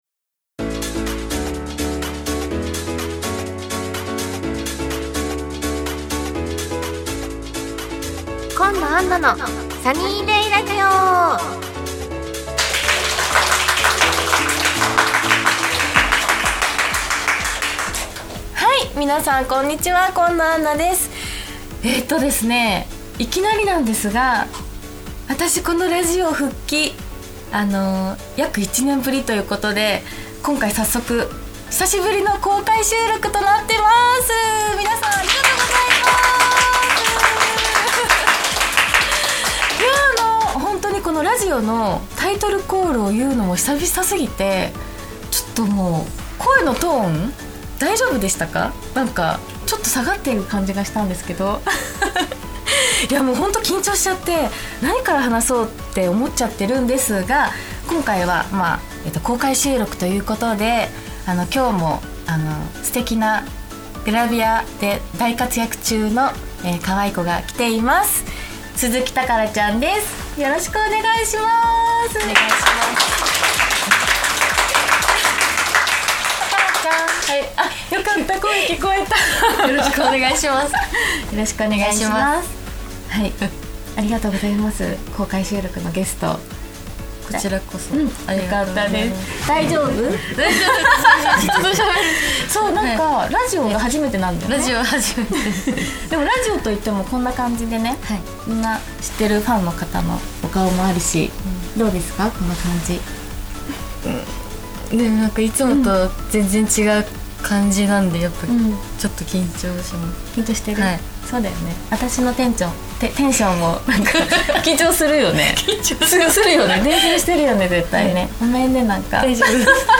約1年ぶりの公開収録＆番組再開です！